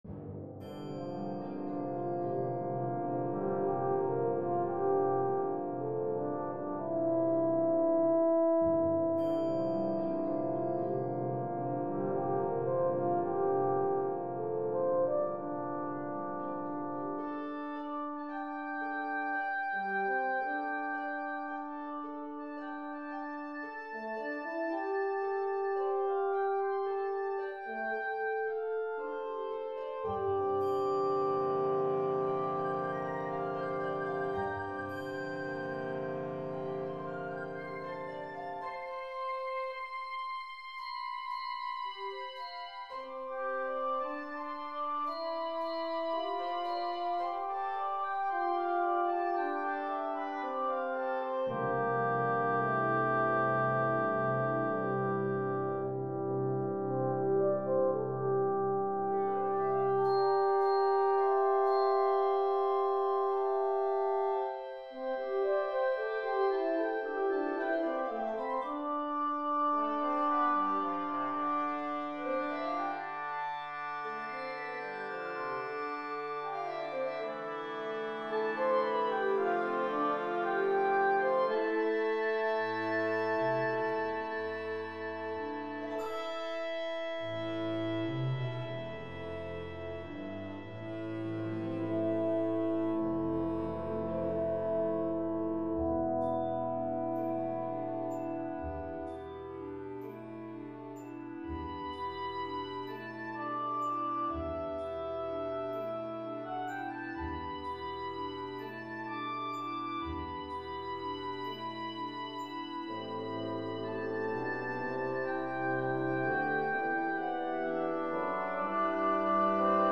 Wind Band
solo for oboe, horn and symphonic band